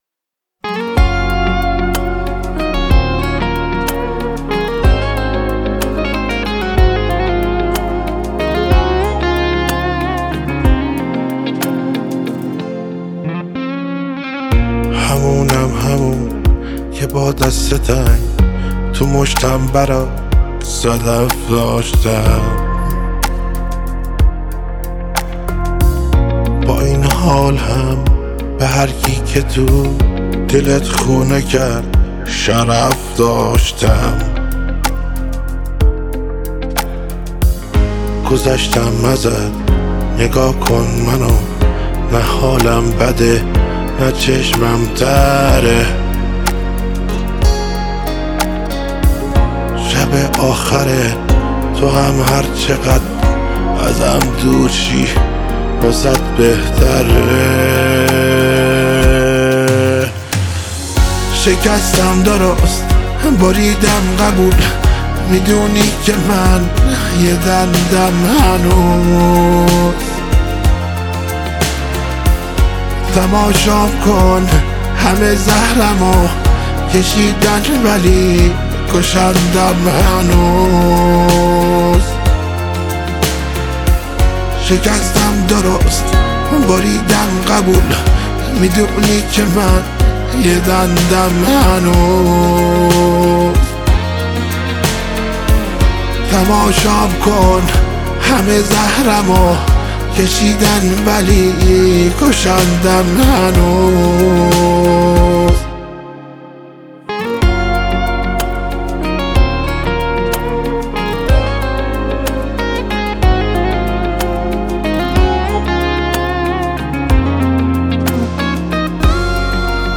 غمگین